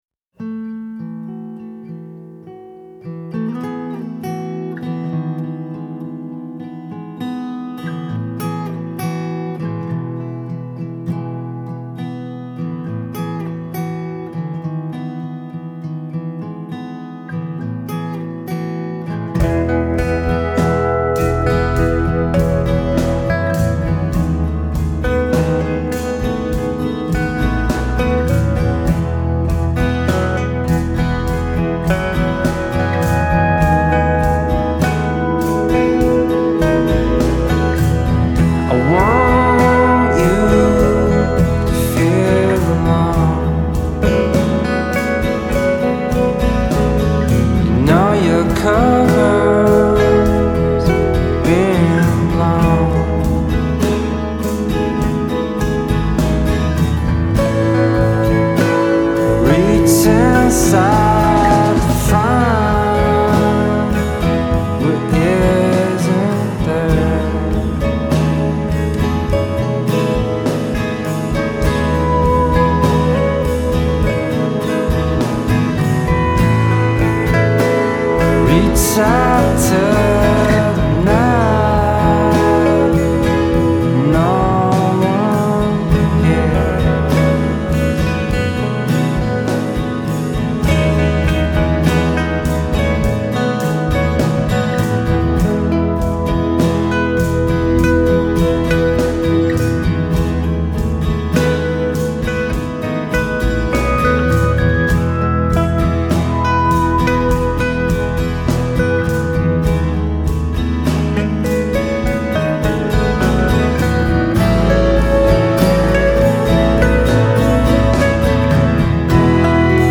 The music that results is mature, nuanced, and beautiful.